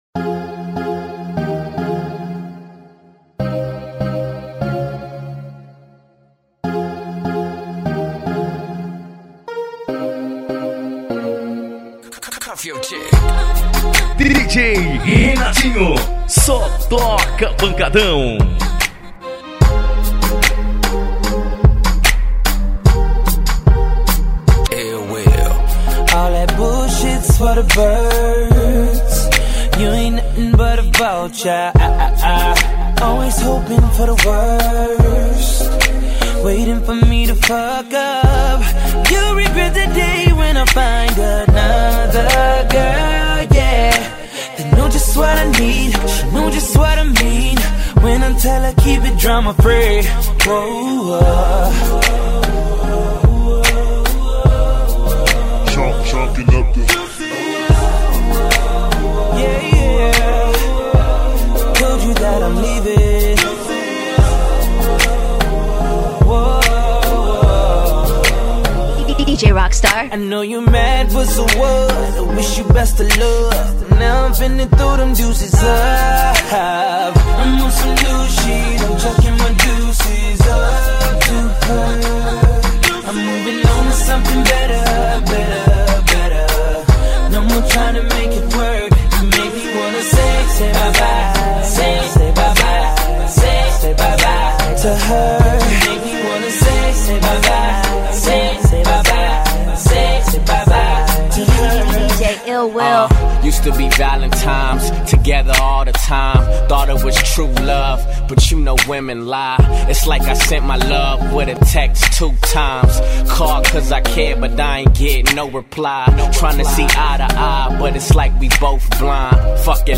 rap gospel.